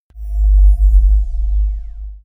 bassstab1.mp3